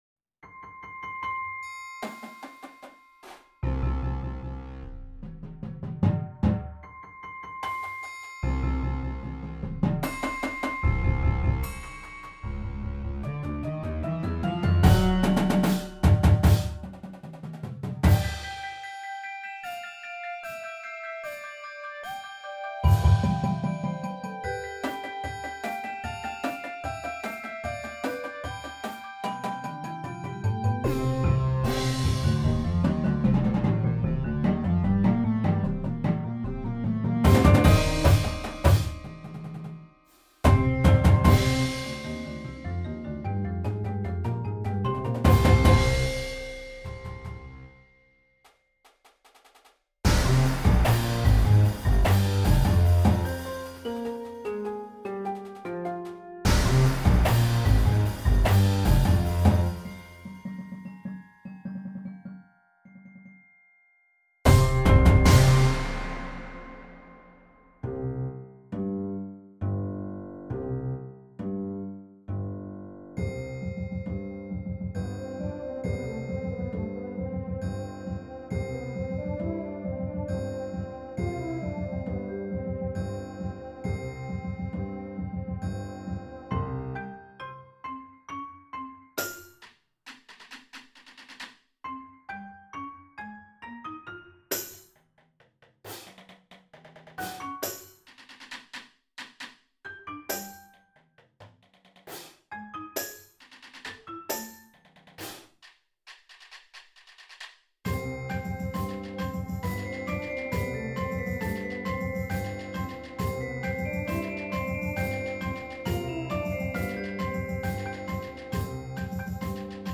scored for large percussion groups